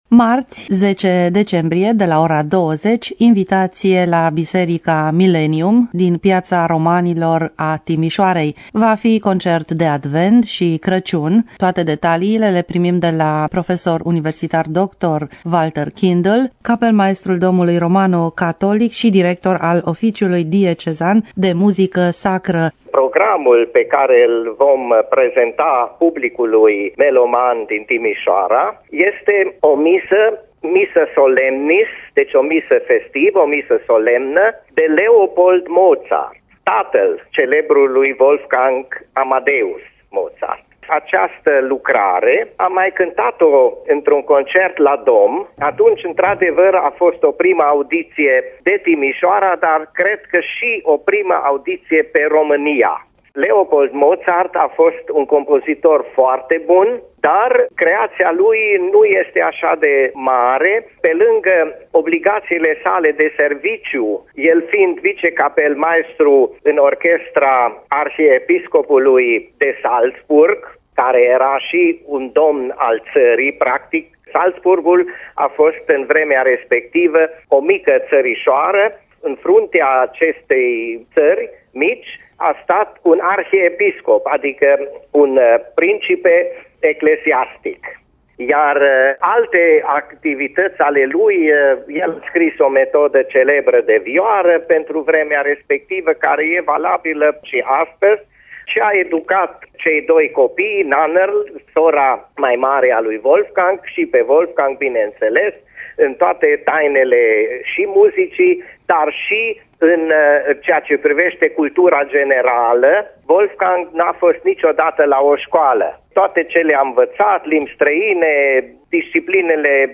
Detalii despre eveniment în dialogul realizat de